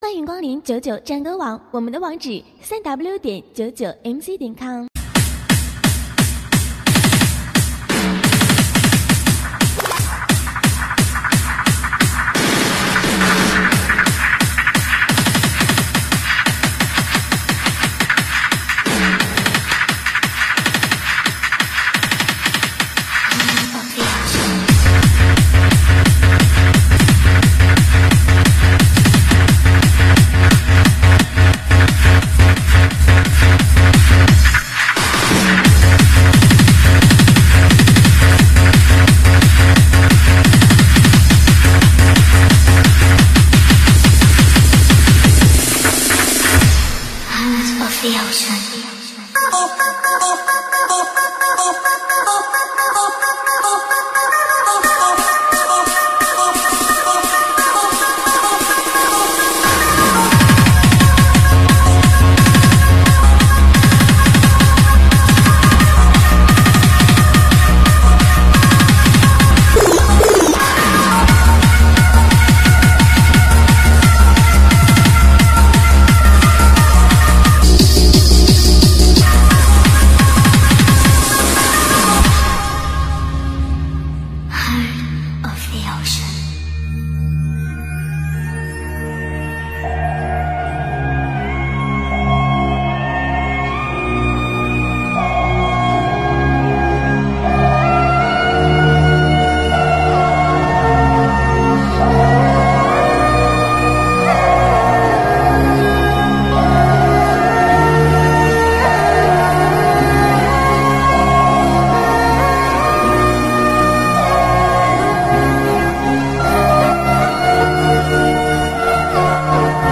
Mc伴奏